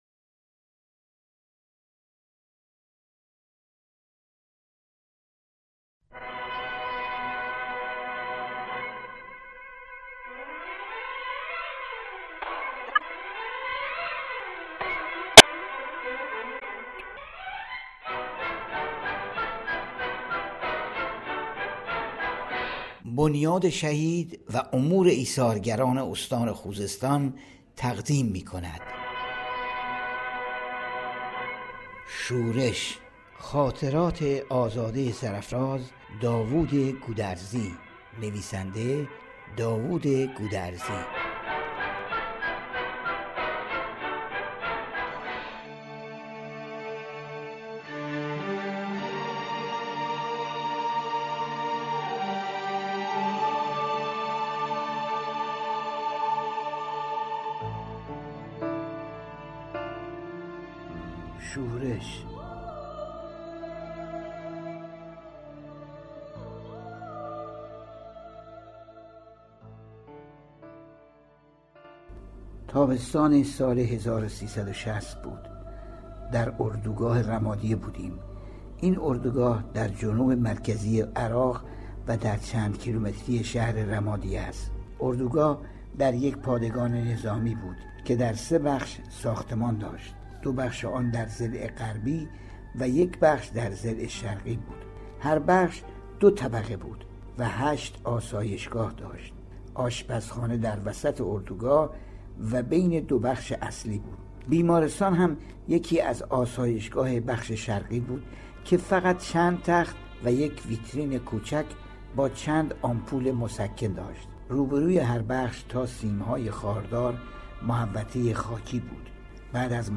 کتاب صوتی